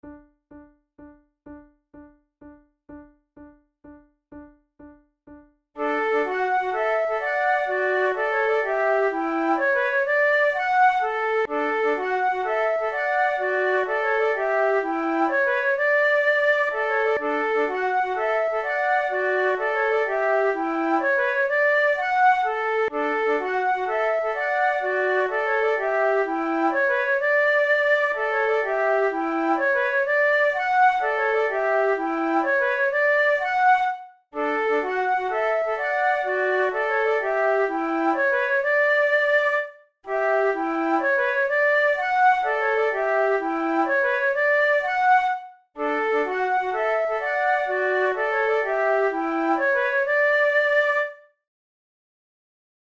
KeyD major
Tempo126 BPM
Play-along accompanimentMIDI (change tempo/key)